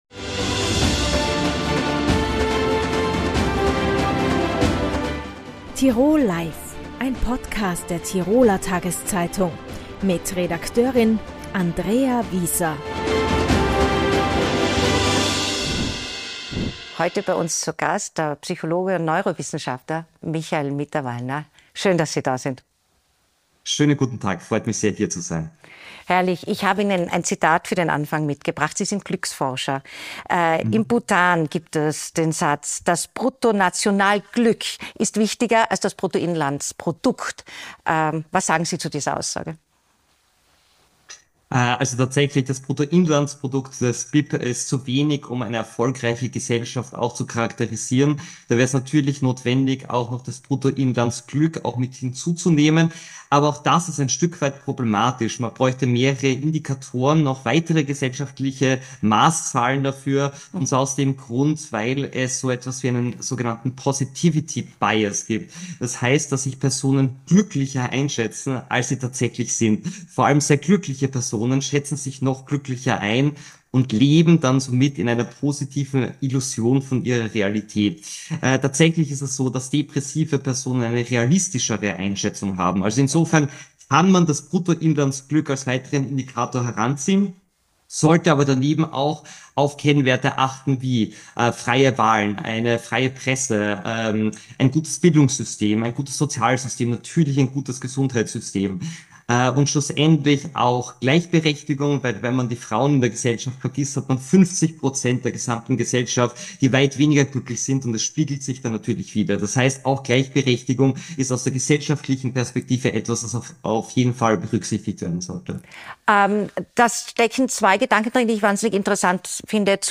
Im Interview spricht er dann eine unbequeme Wahrheit offen aus: